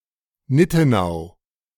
Nittenau (German: [ˈnɪ.tə.naʊ̯]
De-Nittenau.ogg.mp3